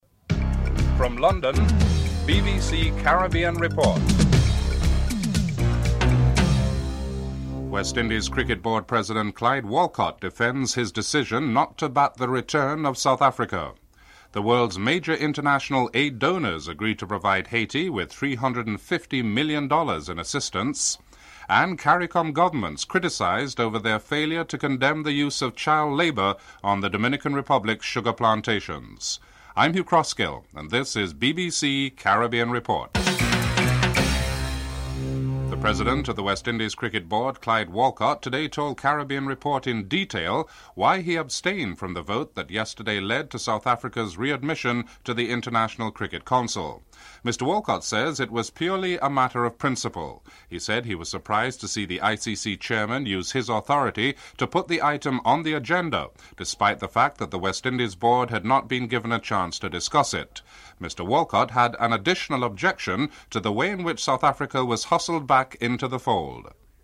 1. Headlines (00:00-00:34)
2. In an interview with Caribbean Report, the West Indies Cricket Board’s President, Clyde Walcott, defends his decision not to support the return of South Africa to the International Cricket Council.